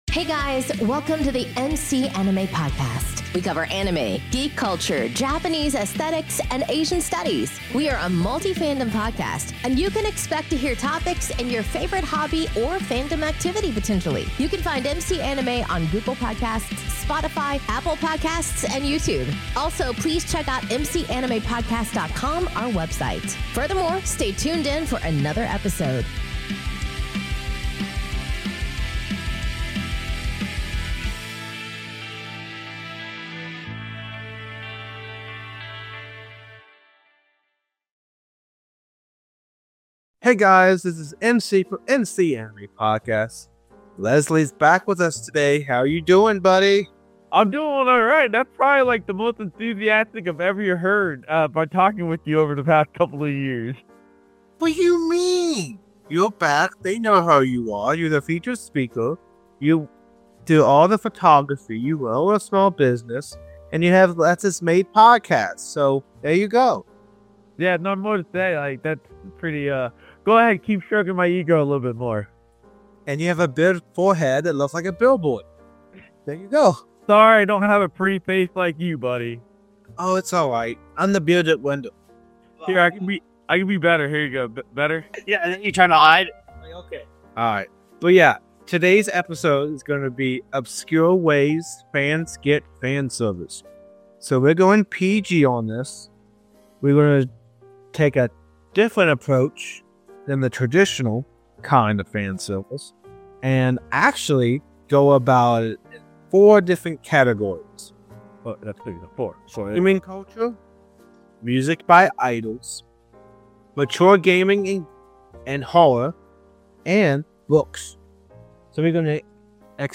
Welcome to our fandom-centric podcast, where we explore anime, geek culture, and dive deep into Japanese aesthetics and Asian Studies. Our family-friendly program features open forum discussions with a conversational, casual tone, offering unique perspectives on the topics that matter most.